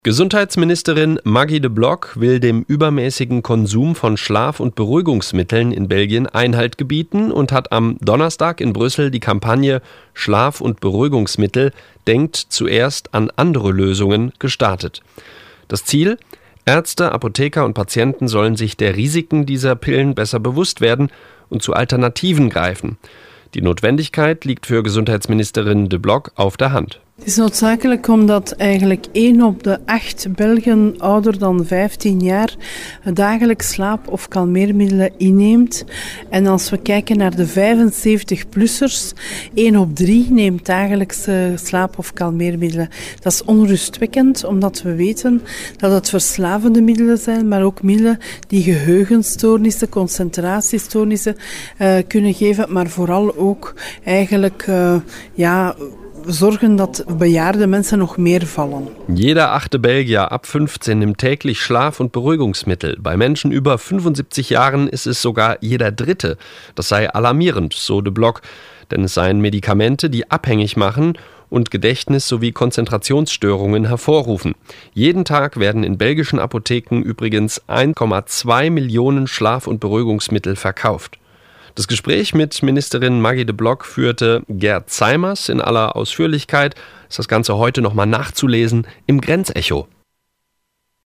sprach darüber mit Gesundheitsministerin Maggie De Block